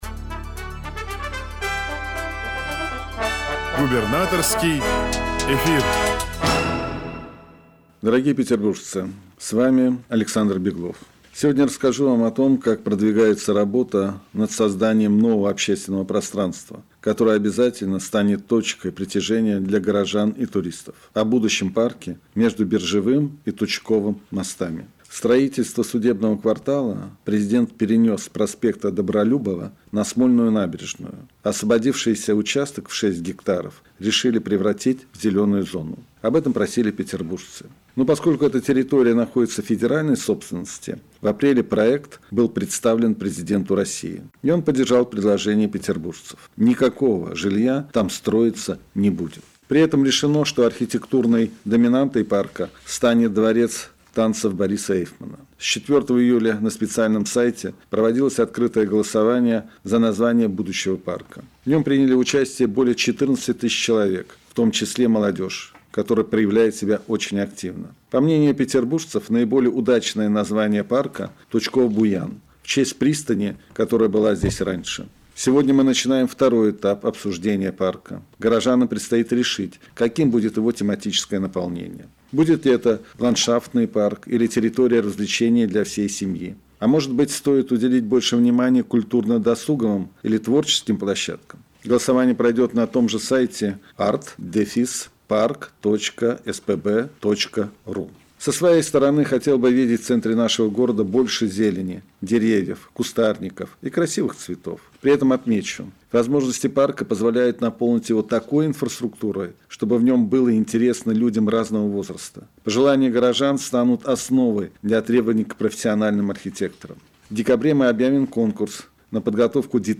Просто в шесть часов вечера 5 августа был назначен так называемый «Губернаторский эфир», двухминутное радиообращение вр.и.о. губернатора Санкт-Петербурга Александра Дмитриевича Беглова (